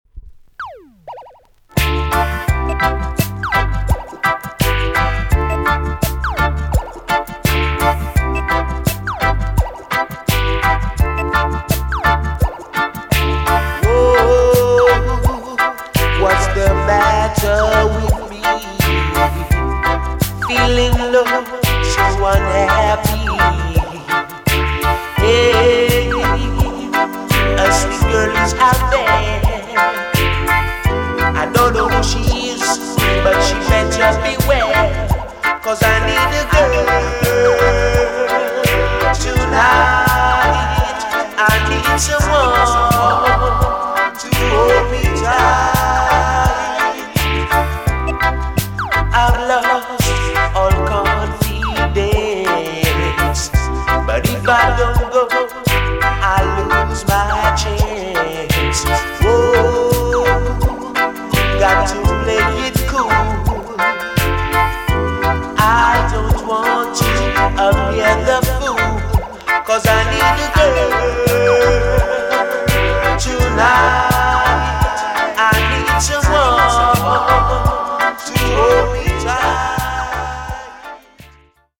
TOP >LOVERS >12 inch , DISCO45
EX- 音はキレイです。